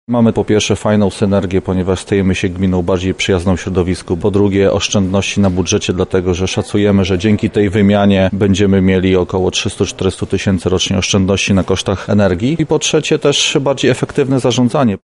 -mówi Burmistrz Nałęczowa Wiesław Pardyka.